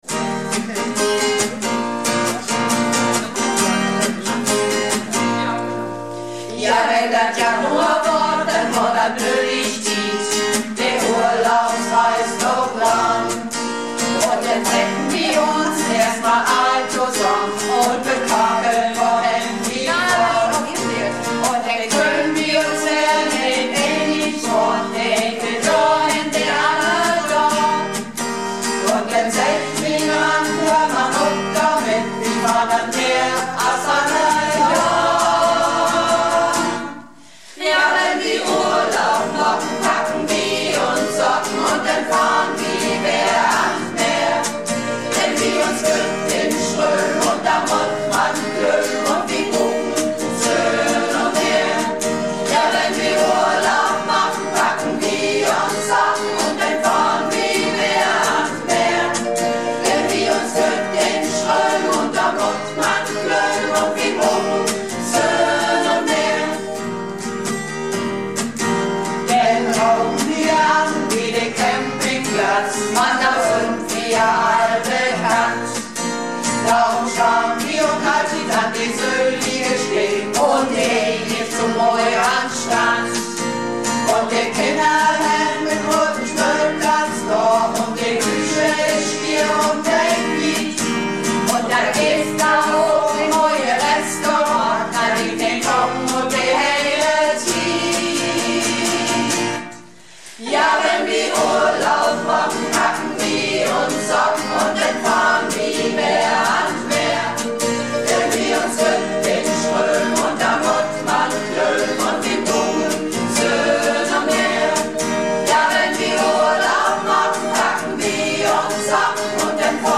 Malle Diven - Probe am 29.01.20